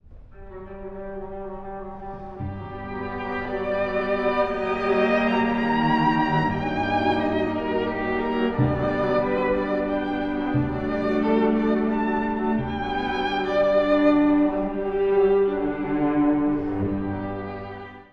(第一主題) 古い音源なので聴きづらいかもしれません！
ビオラの揺れ動く8分の上で、バイオリンのたゆたうような第一主題が奏でられます。
軽やかで夢見るような響きの中に、内に秘めた情熱も見え隠れします。
ふたつの調性が混ざり合い、深い色を見せます。